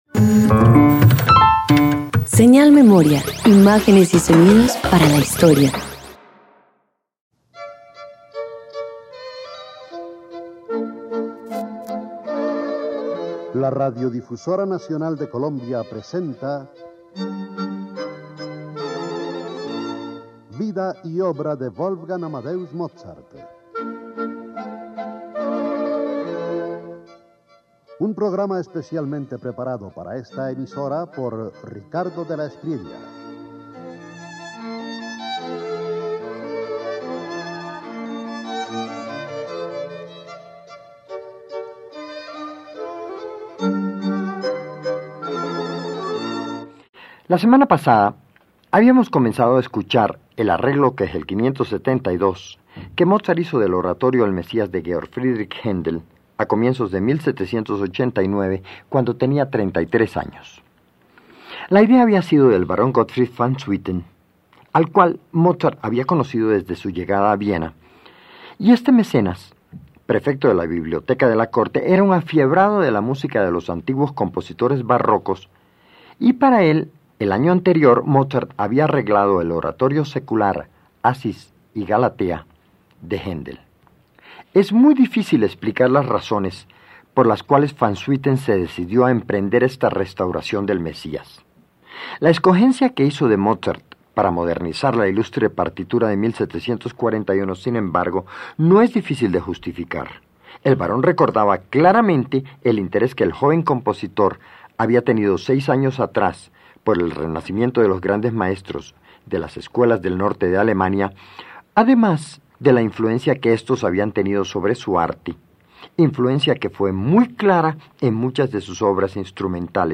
296 Oratorio El Mesías de Handel reorquestado por Mozart Parte II_1.mp3